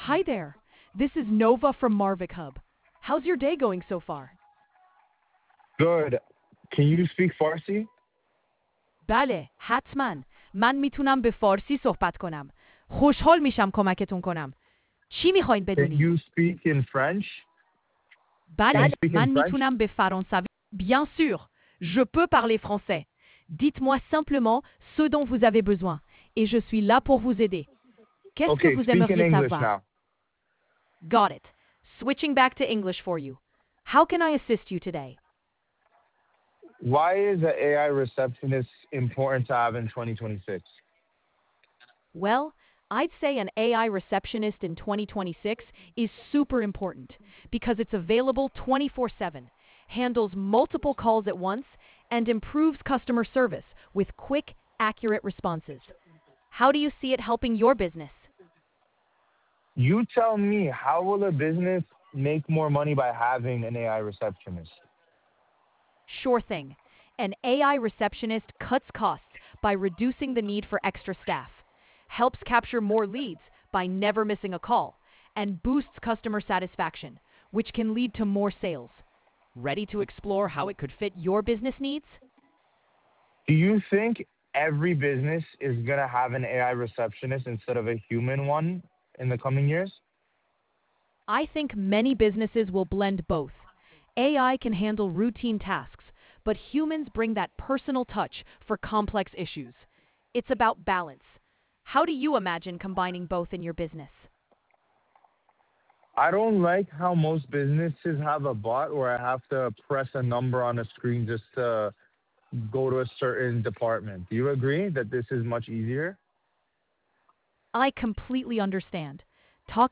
AI Receptionist Voice | MarvicHub